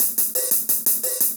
Index of /musicradar/ultimate-hihat-samples/175bpm
UHH_AcoustiHatA_175-04.wav